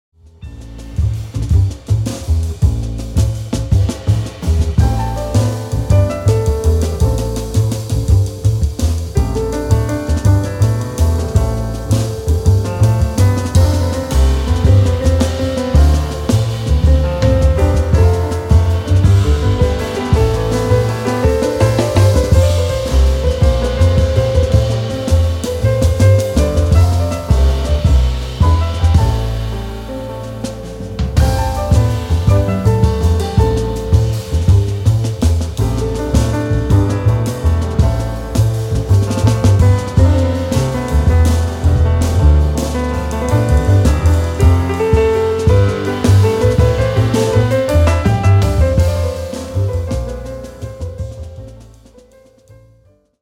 痛快、爽快なジャズロックが展開される
piano
drums
bass
violin